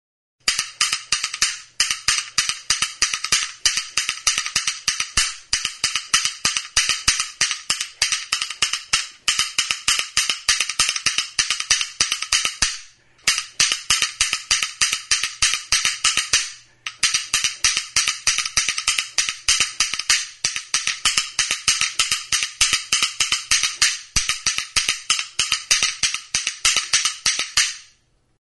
Recorded with this music instrument.
TEXOLETAS; TARRAÑOLAS
Idiophones -> Struck -> Indirectly
WOOD